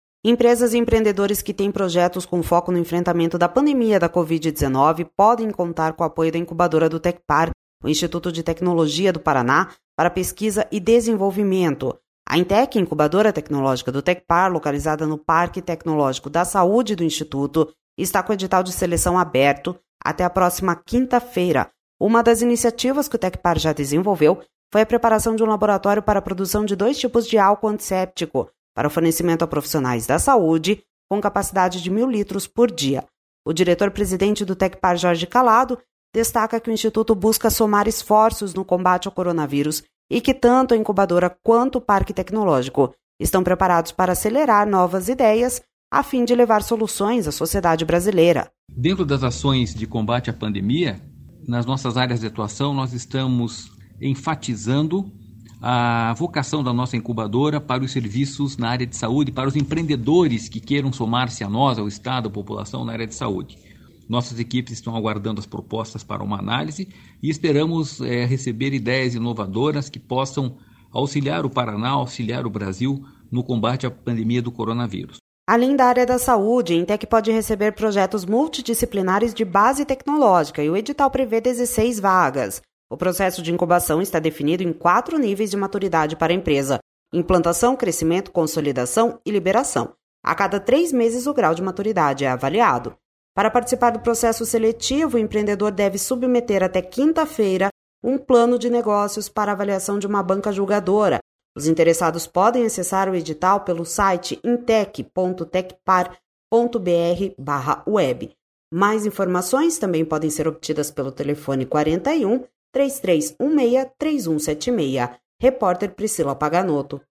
O diretor-presidente do Tecpar, Jorge Callado, destaca que o instituto busca somar esforços no combate ao coronavírus e que tanto a incubadora quanto o parque tecnológico estão preparados para acelerar novas ideias a fim de levar soluções à sociedade brasileira.// SONORA JORGE CALLADO//